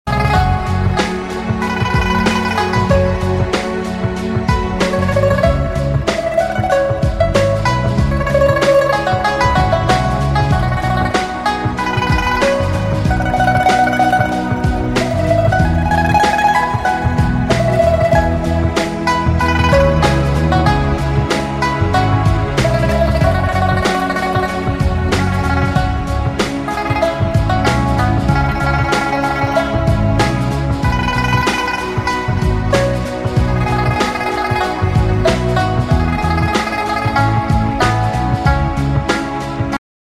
спокойные
инструментальные